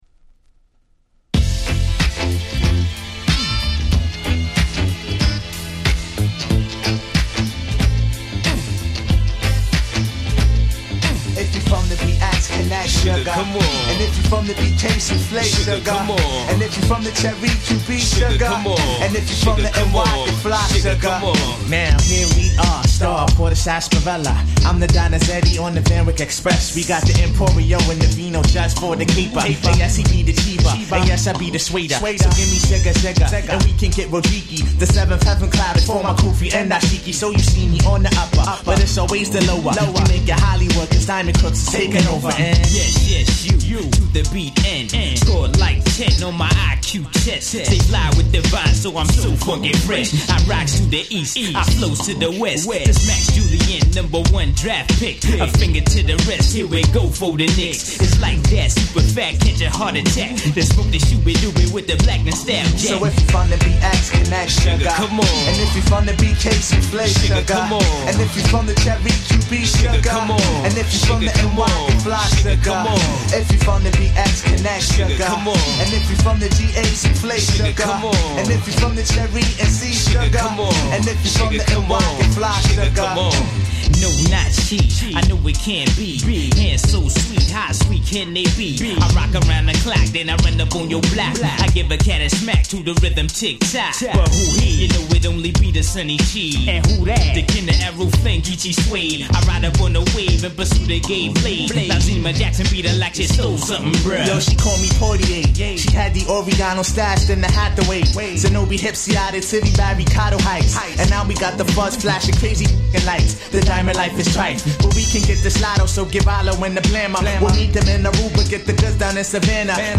97' Smash Hit Hip Hop !!
Classic Rare Groove
キャンプロー 90's Boom Bap ブーンバップ